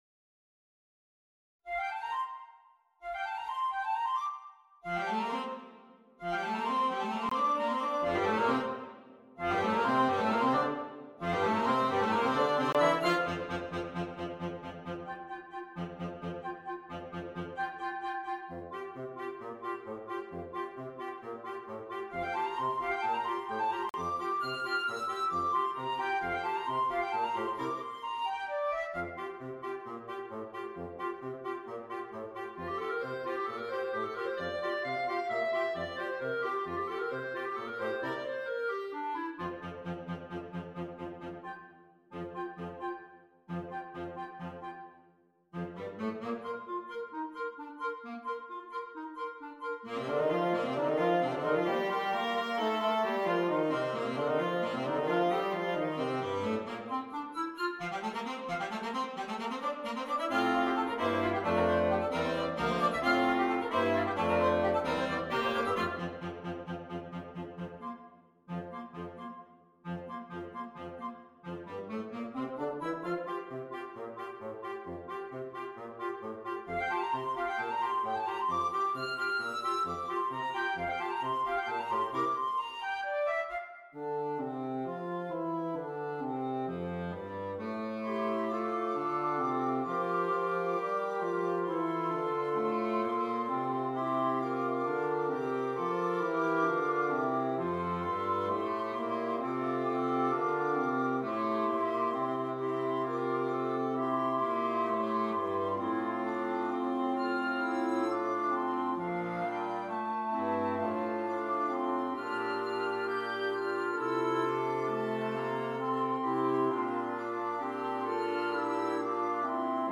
Interchangeable Woodwind Ensemble
PART 1 - Flute, Oboe, Clarinet
PART 4 - Clarinet, Alto Saxophone, Tenor Saxophone, F Horn
PART 5 - Bass Clarinet, Bassoon, Baritone Saxophone